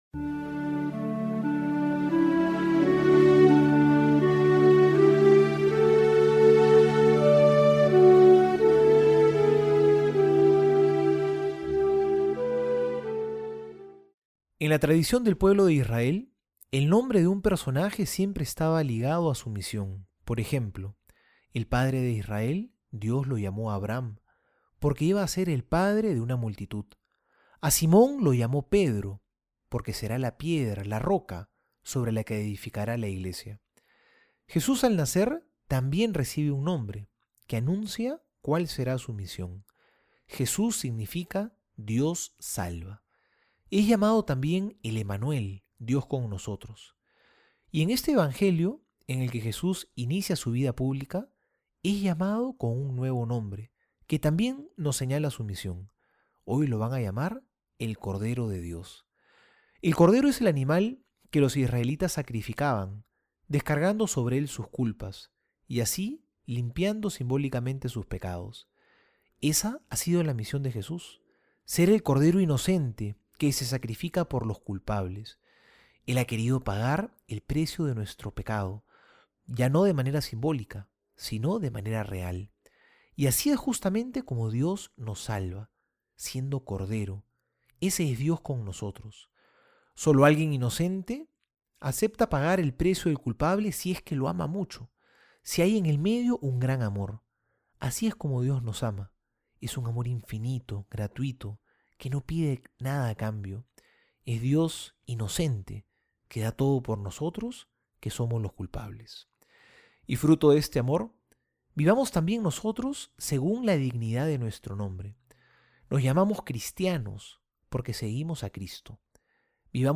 Homilía para hoy:
Homilia Enero 03.mp3